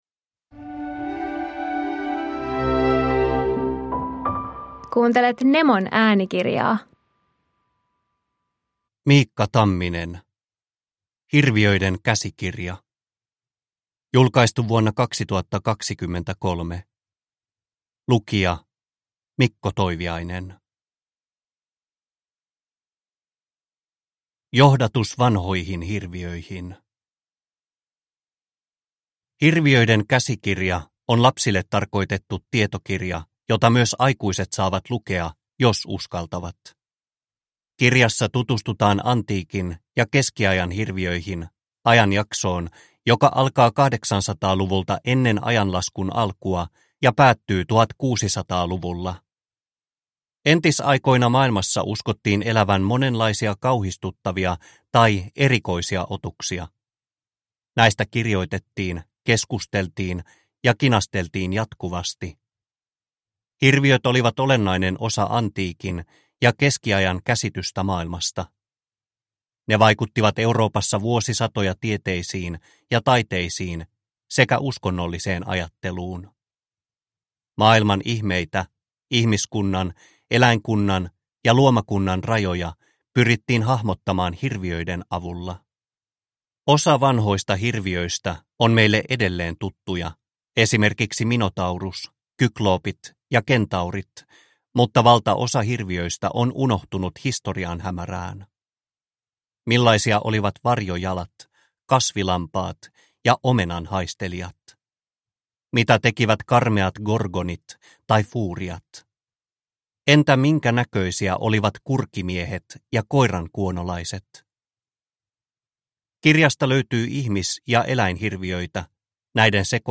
Hirviöiden käsikirja – Ljudbok – Laddas ner